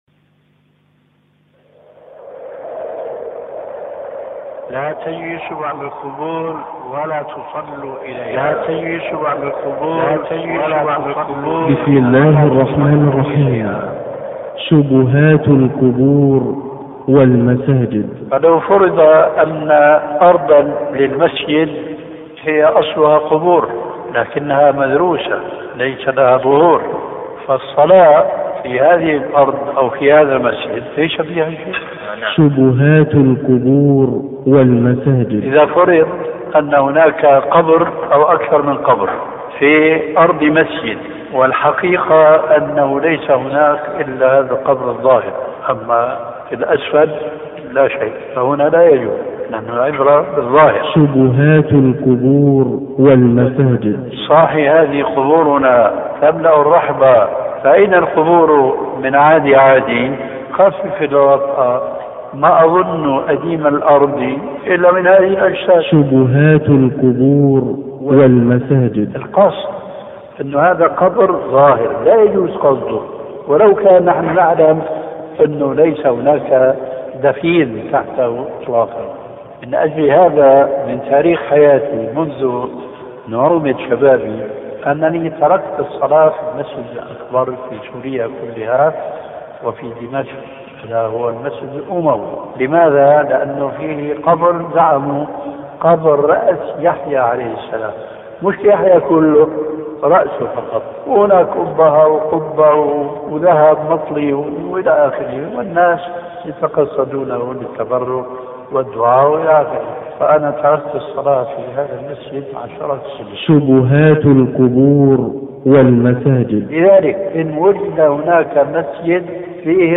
شبكة المعرفة الإسلامية | الدروس | شبهات القبور والمساجد |محمد ناصر الدين الالباني